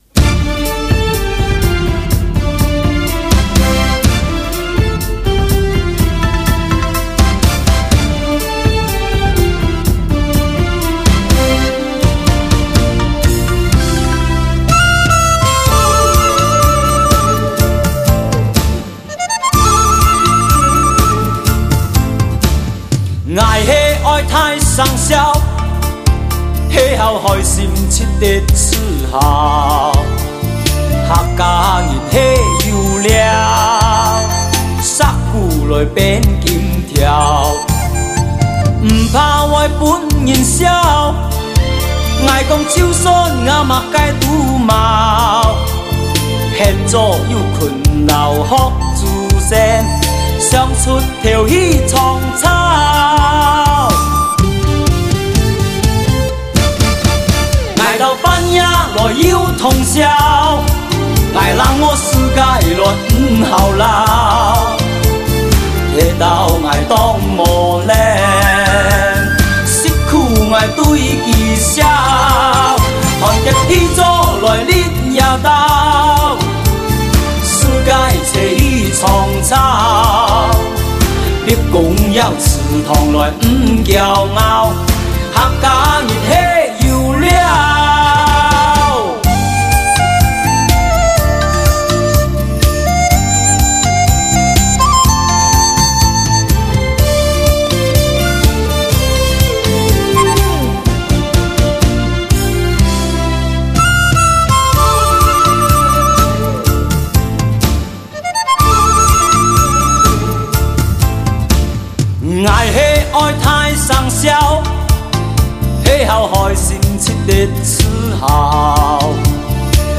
[13/12/2008]客家歌曲《客家人系有料》